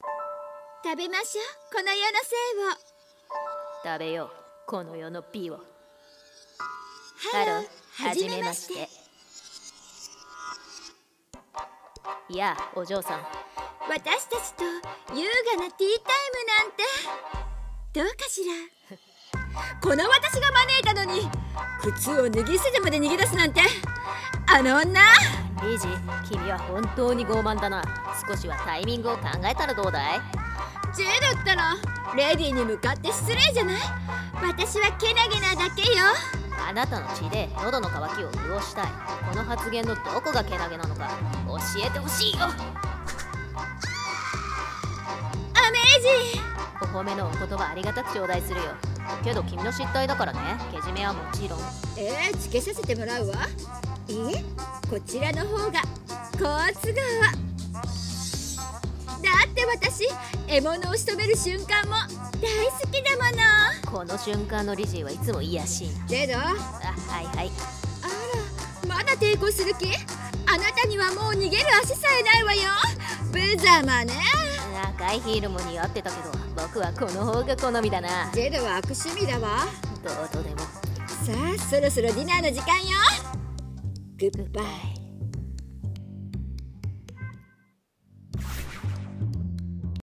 【声劇コラボ】Hello. Please die 【掛け合い】